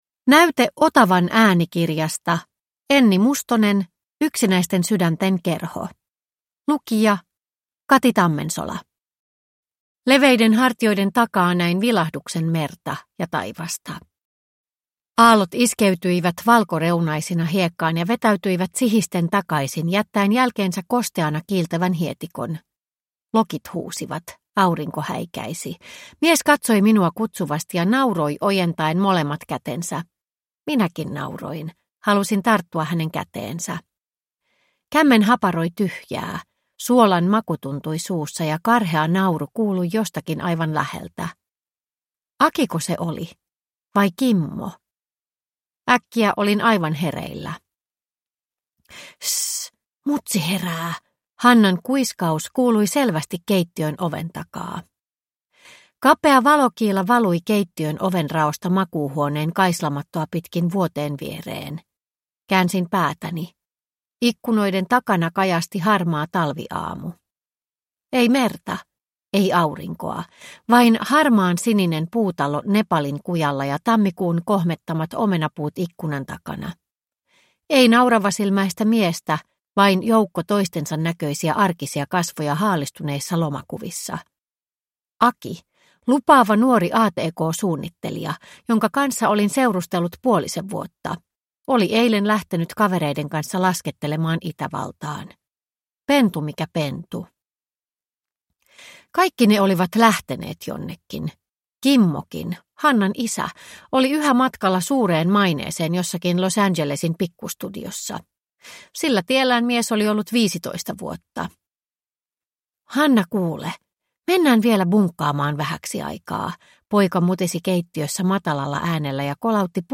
Yksinäisten sydänten kerho – Ljudbok